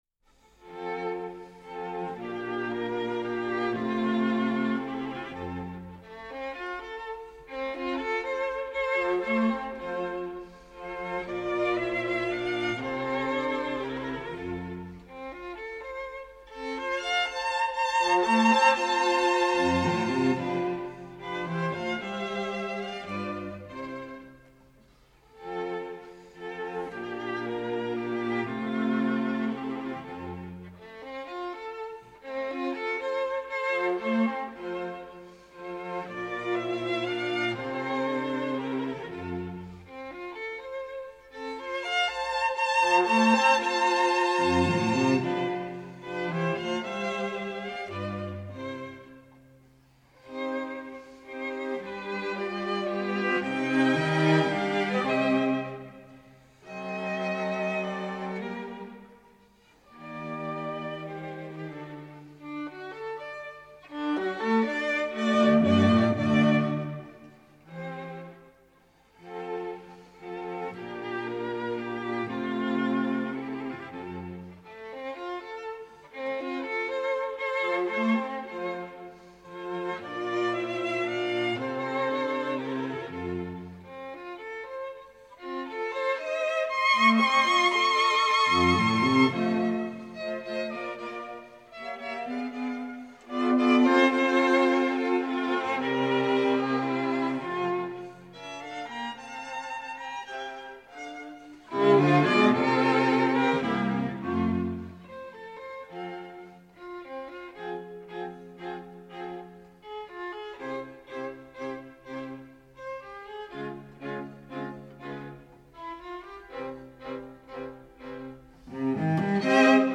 String Quartet in D minor
Andante